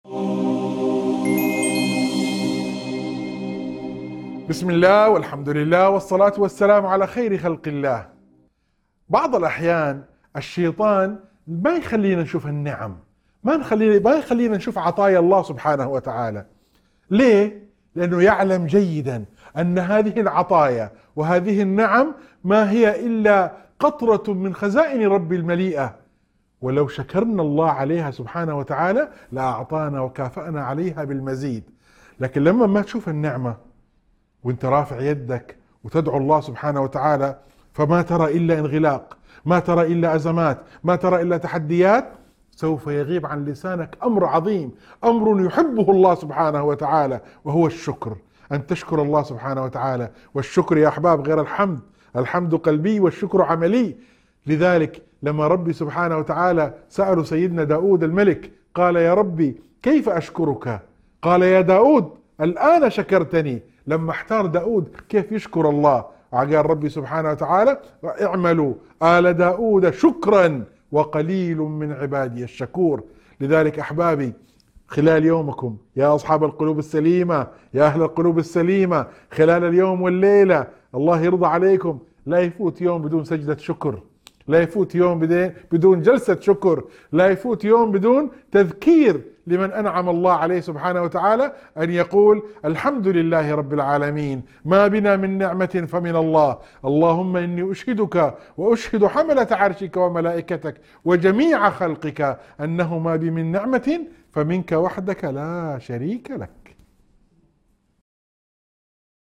مقطع وعظي يذكر بنعم الله الكثيرة ويحث على شكرها، موضحاً أن الشكر سبب للمزيد من العطاء. يسلط الضوء على خطر وسوسة الشيطان التي تحول دون رؤية هذه النعم، ويوجه إلى ضرورة المداومة على سجود الشكر وذكر الله على إحسانه.